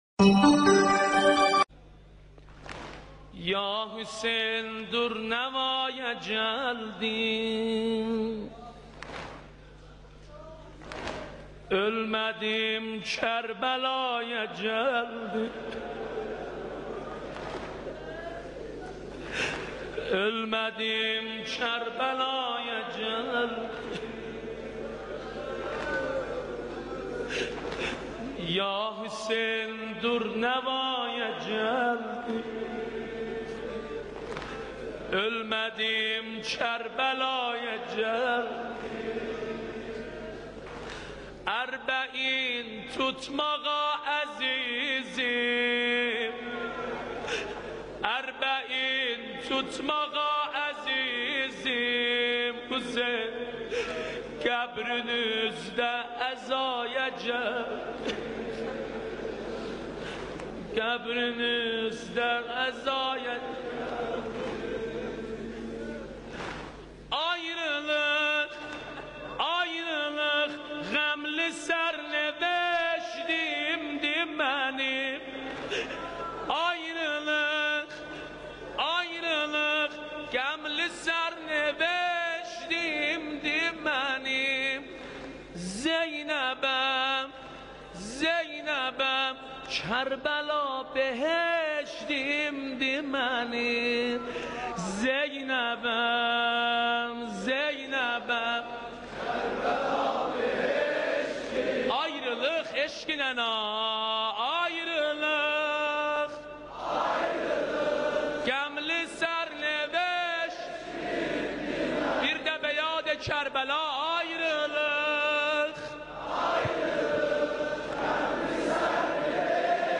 مداحی آذری نوحه ترکی
در محضر رهبر معظم انقلاب